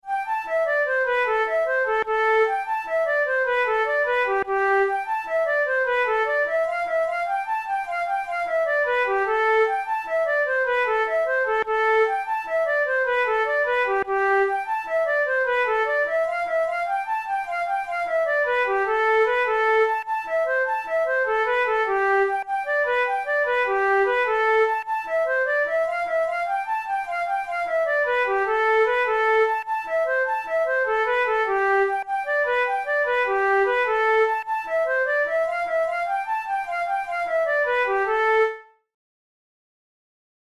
Traditional Irish jig